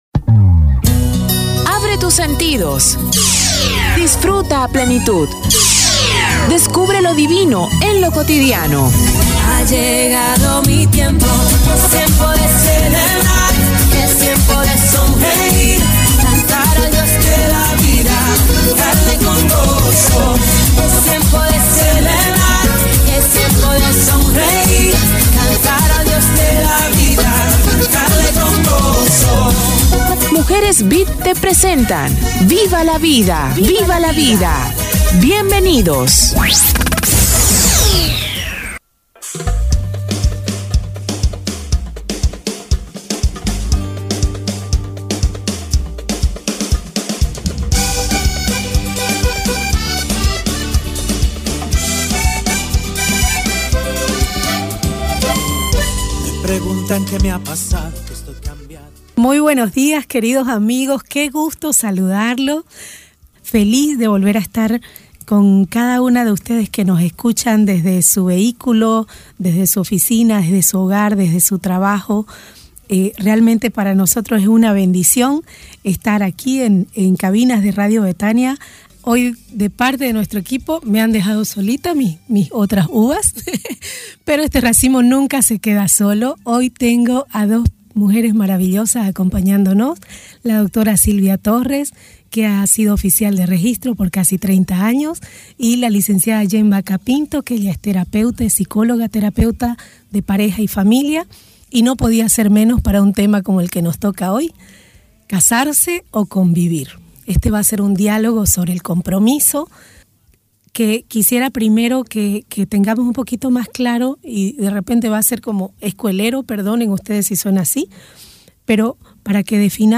¿Casarse o convivir?, diálogo sobre el compromiso - Radio Betania